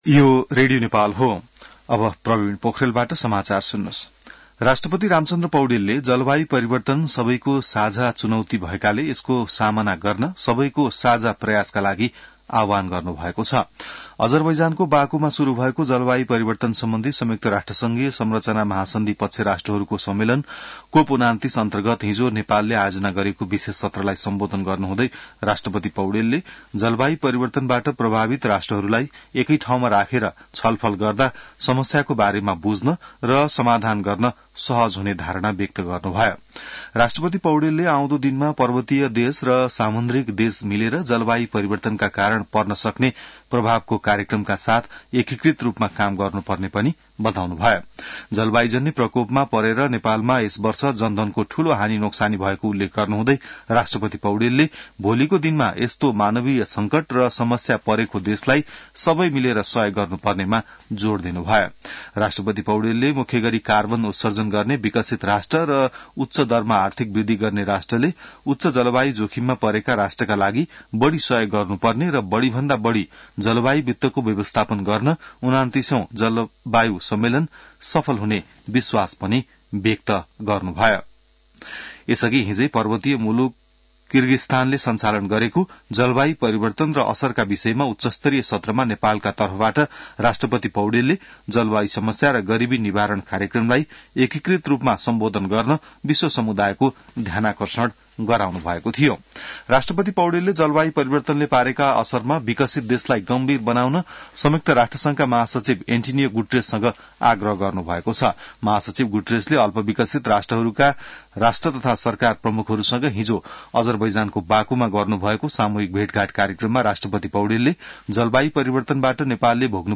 बिहान ६ बजेको नेपाली समाचार : ३० कार्तिक , २०८१